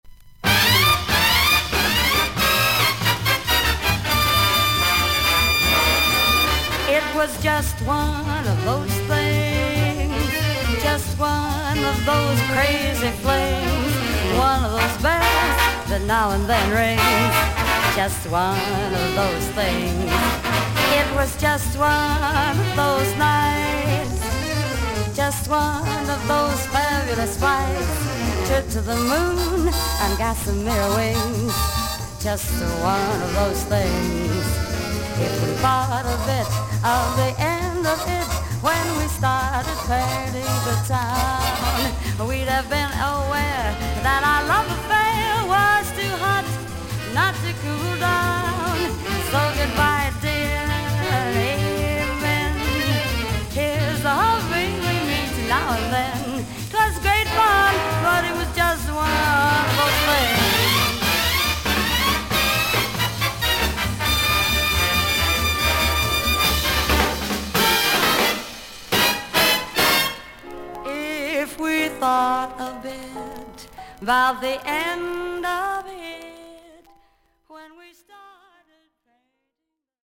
少々軽いパチノイズの箇所あり。少々サーフィス・ノイズの箇所あり。クリアな音です。
女性ジャズ・シンガー。